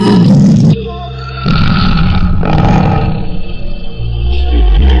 Страшный рёв монстра:
monsters2.wav